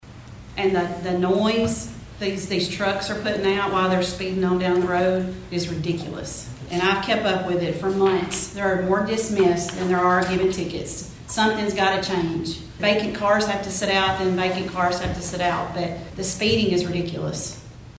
At Dresden’s City Board meeting this week, Alderman Curtis Doran questioned Dresden Police Chief Bryan Chandler on what will be done about speeding in Dresden.
Alderwoman Jennifer Schlicht agreed….